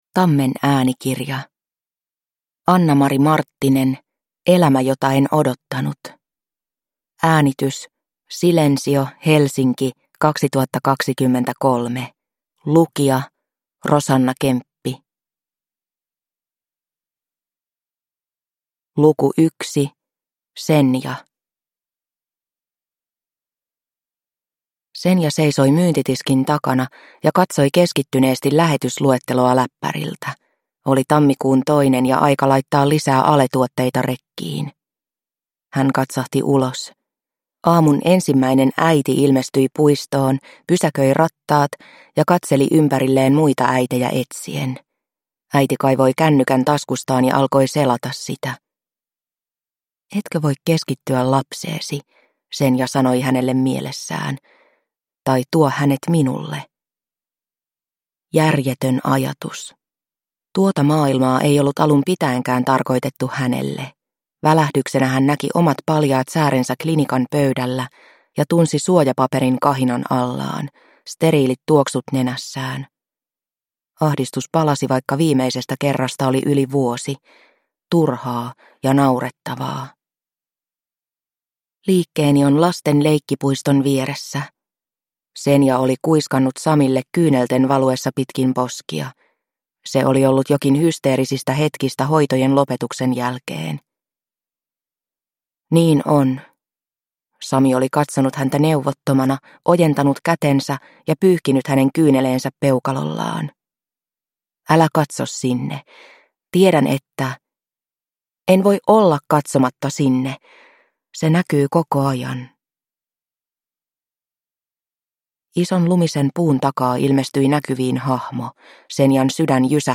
Elämä jota en odottanut (ljudbok) av Annamari Marttinen